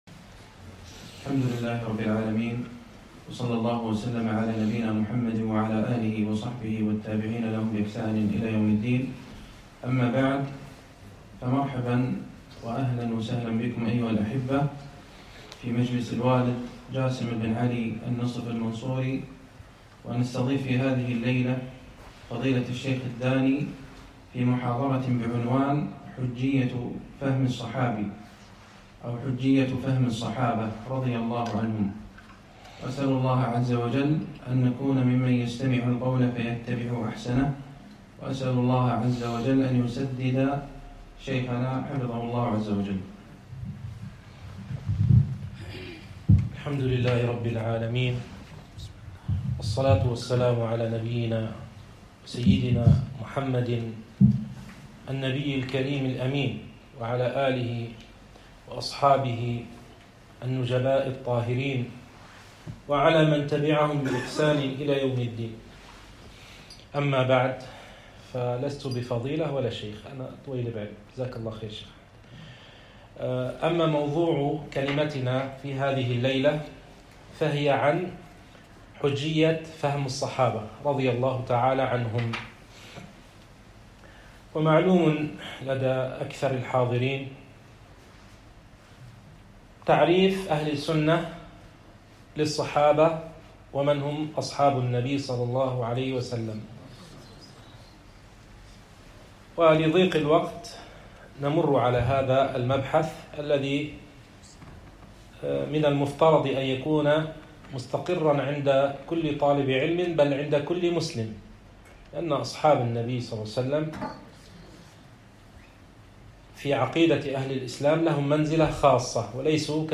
حجية فهم الصحابة - محاضرة بدولة قطر